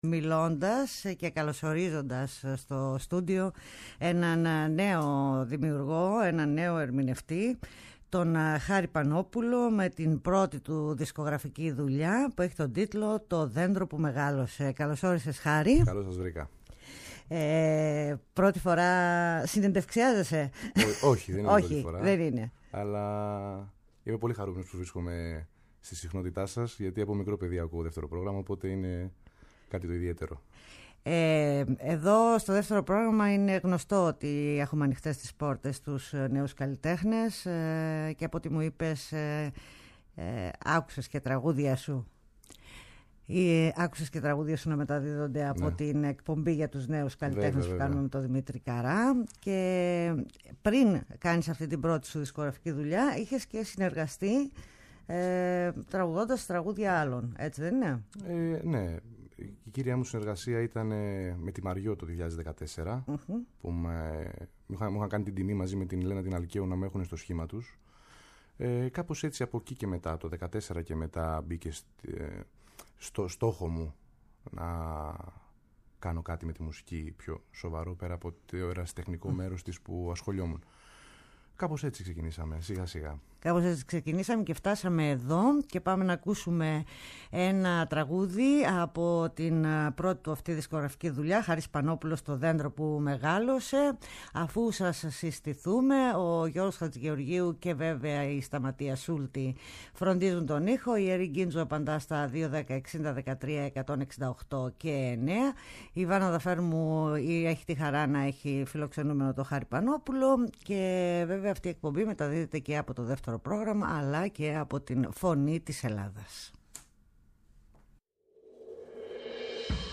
ΔΕΥΤΕΡΟ ΠΡΟΓΡΑΜΜΑ Παντος Καιρου Μουσική Συνεντεύξεις